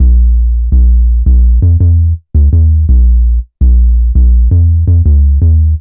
Index of /90_sSampleCDs/Zero-G - Total Drum Bass/Instruments - 1/track06 (Bassloops)